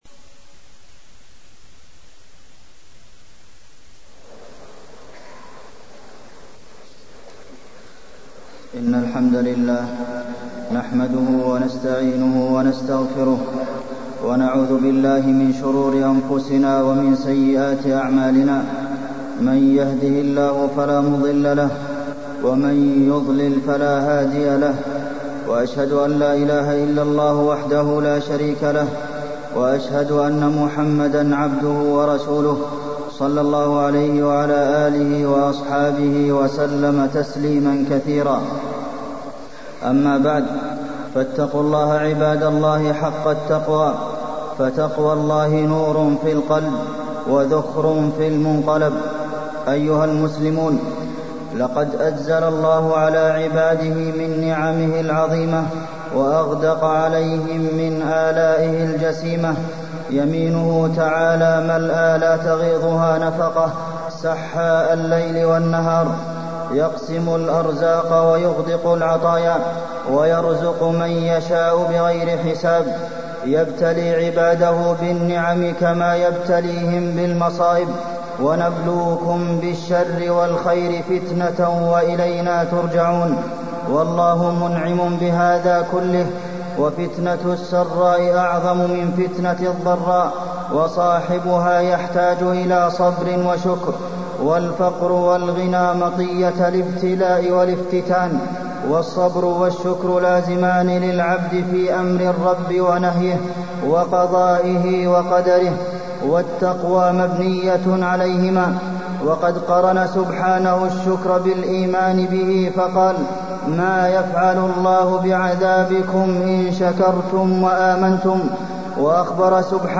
تاريخ النشر ٢٣ شوال ١٤٢٣ هـ المكان: المسجد النبوي الشيخ: فضيلة الشيخ د. عبدالمحسن بن محمد القاسم فضيلة الشيخ د. عبدالمحسن بن محمد القاسم الـشكر